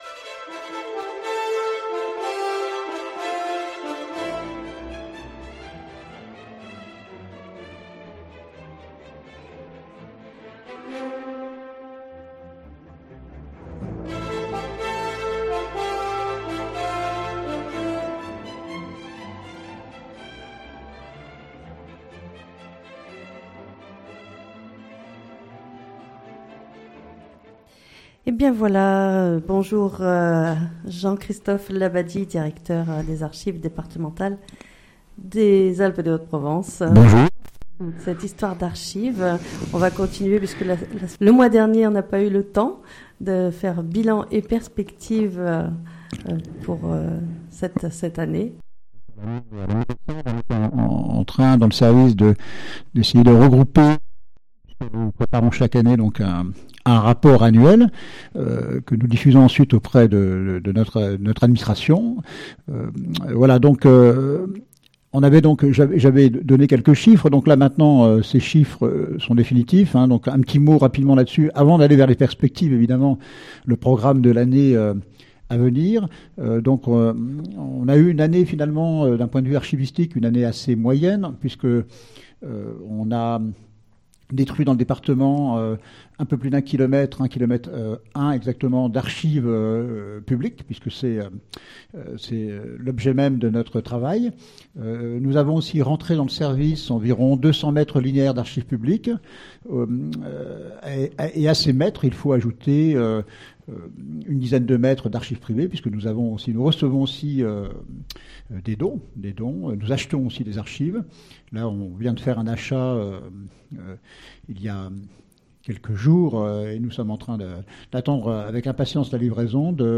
en direct de 9h10 à 10h